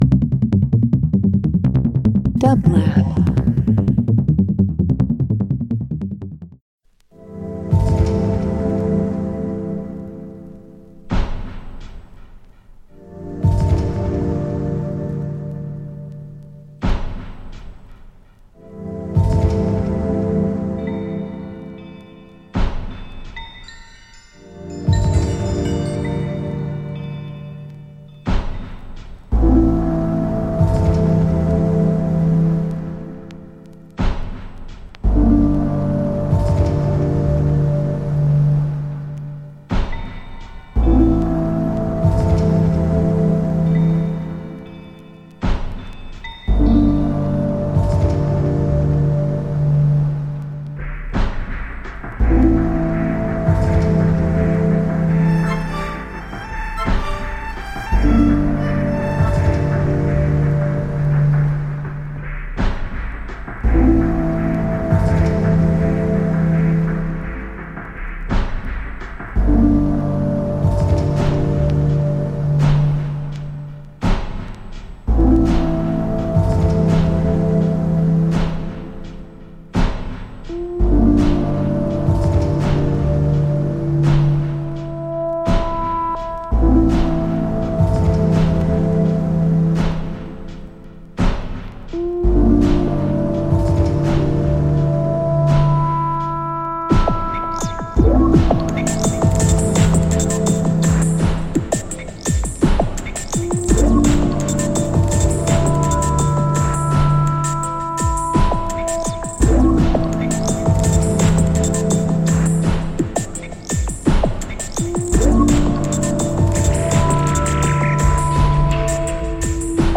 Alternative Electronic Indie